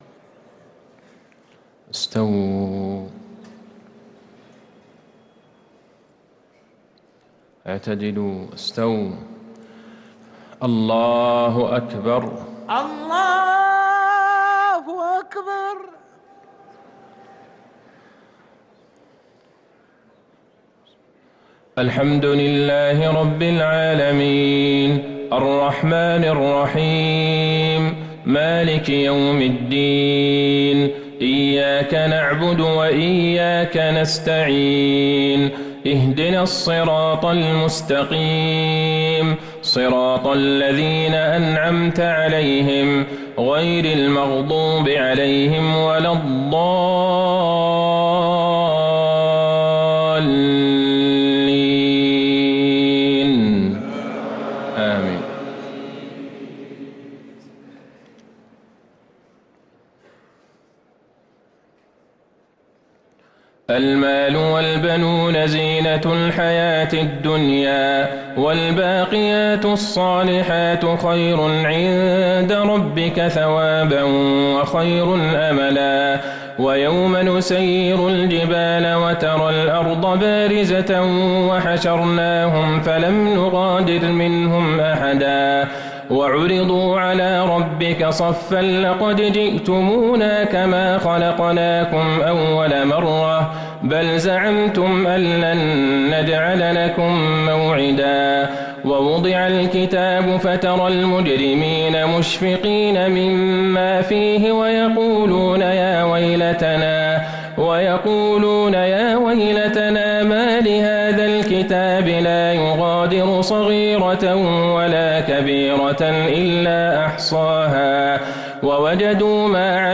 صلاة العشاء للقارئ عبدالله البعيجان 13 شعبان 1444 هـ